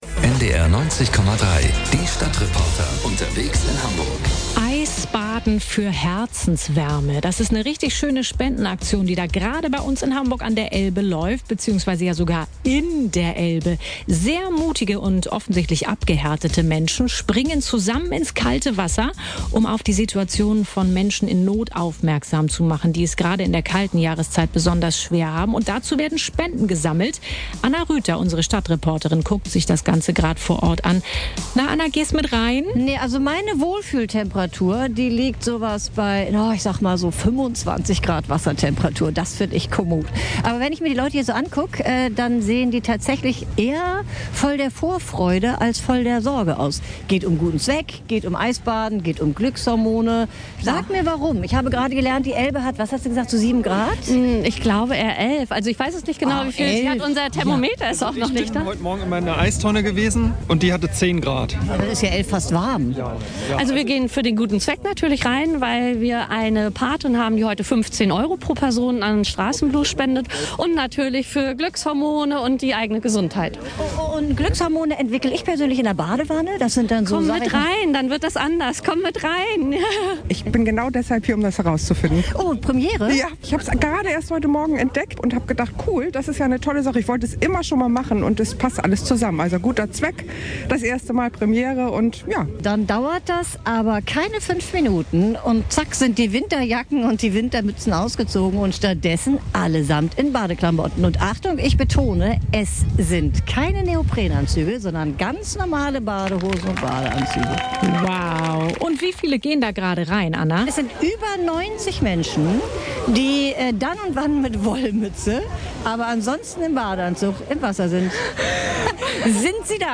Reporter vom Sender NDR 90.3 berichten live vor Ort
eisbademeisters-ndr-report-1138.mp3